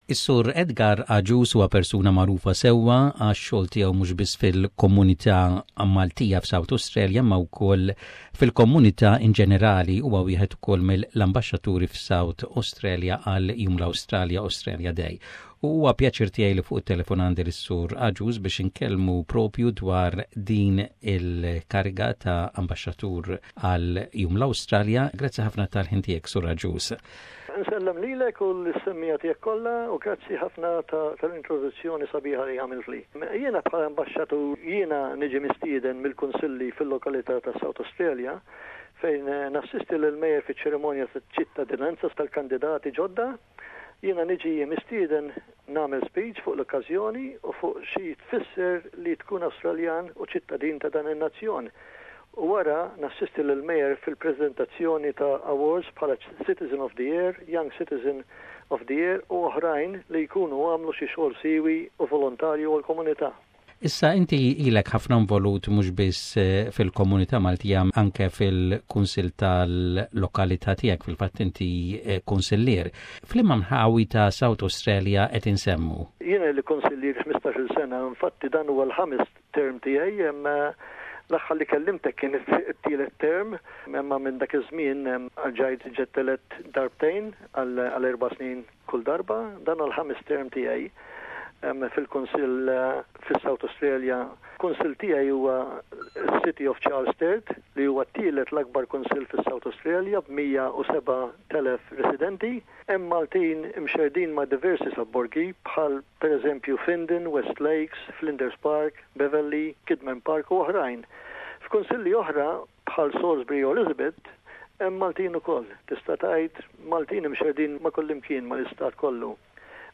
Councilor, Edgar Agius speaks